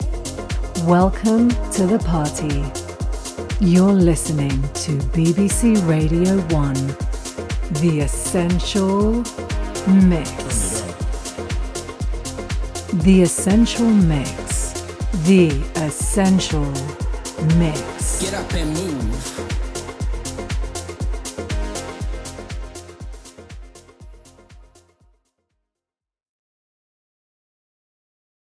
Female
Movie Trailers
A Voice Demo Highlighting A Deep, Sophisticated, Emotional, Warm, And Edgy Vocal Style.